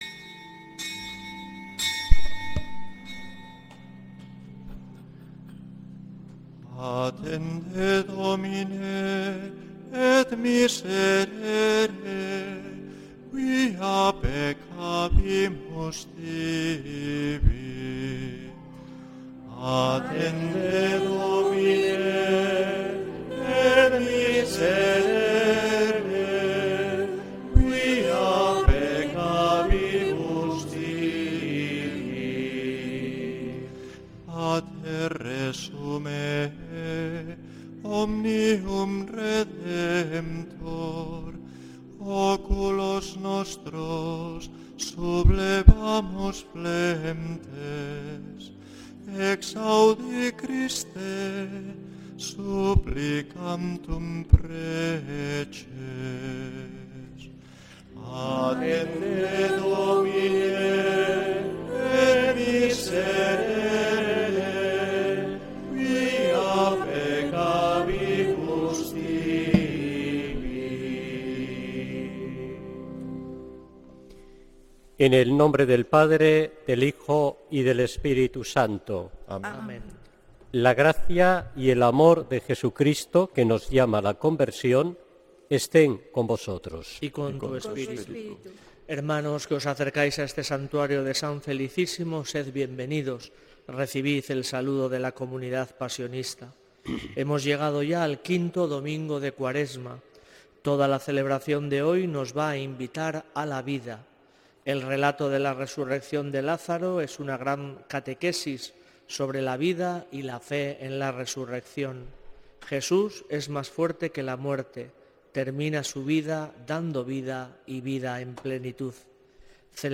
Santa Misa desde San Felicísimo en Deusto, domingo 22 de marzo de 2026